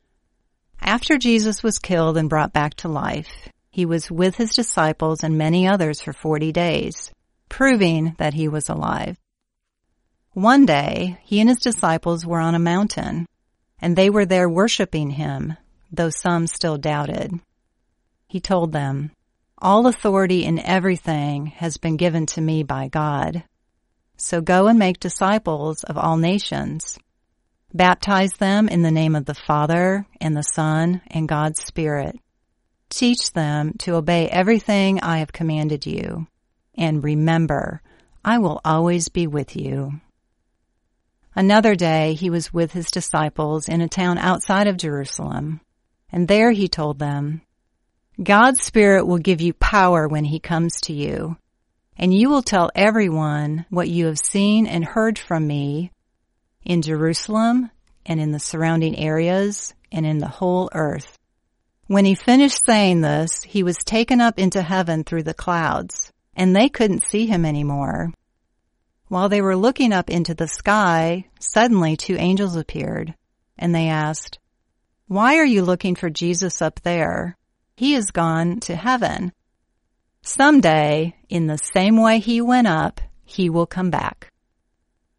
Deepen your intimacy with God by listening to an oral Bible story each day.